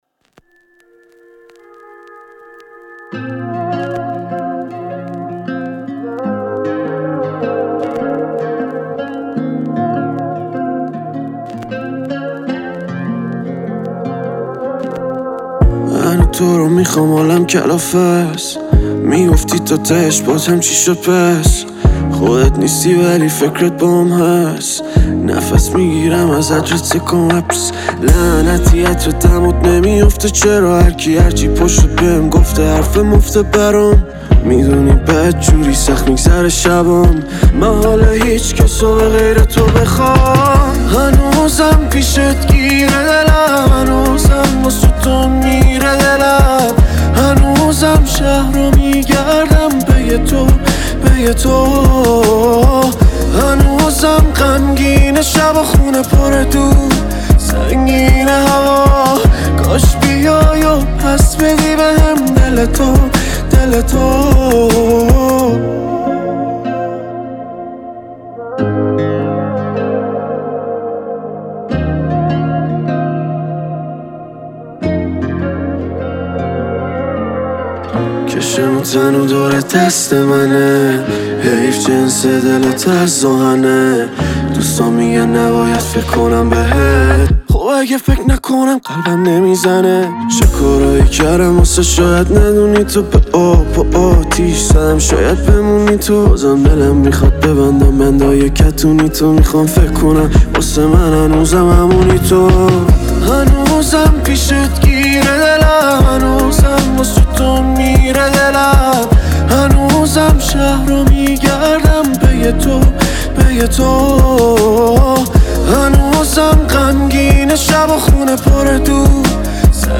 یه موزیک کاملا عاشقانه وجذابی هست